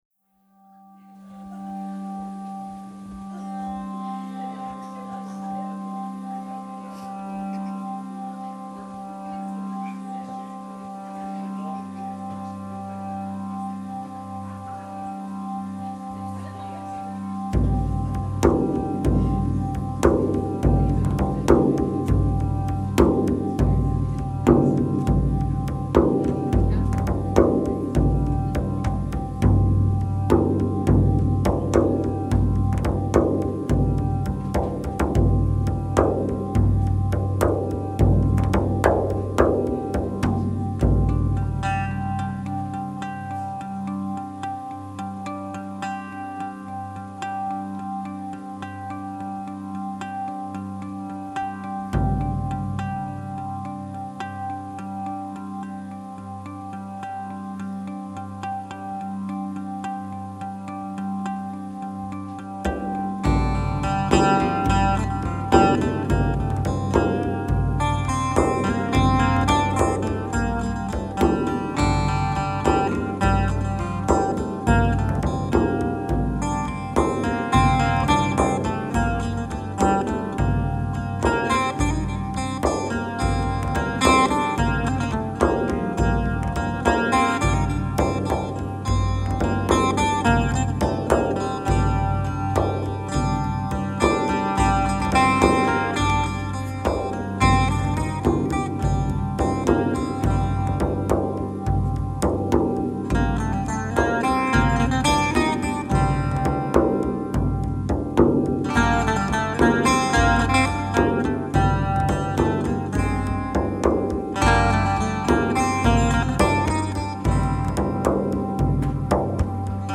Recorded live in Chesea VT 11/19/06